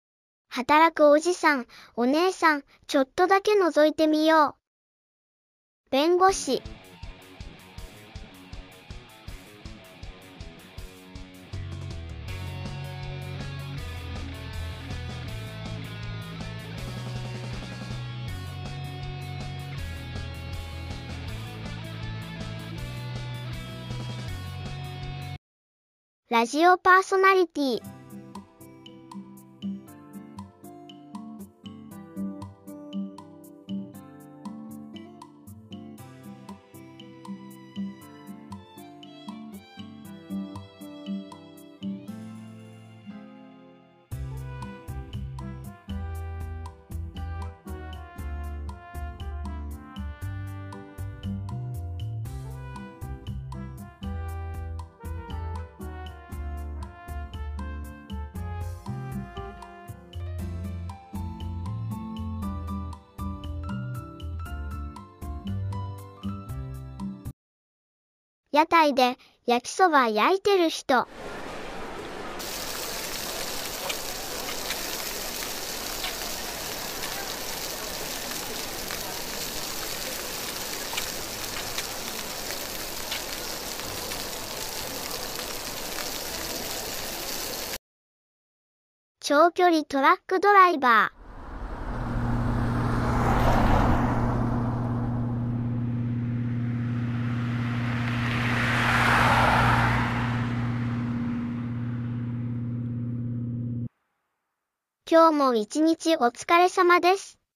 【1人劇】働くおじさん/お姉さん